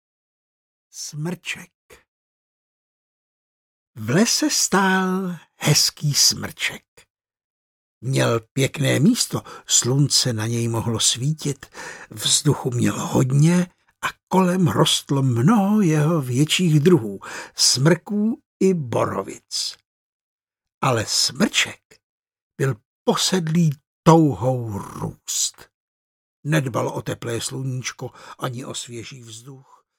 Smrček audiokniha
Ukázka z knihy
smrcek-audiokniha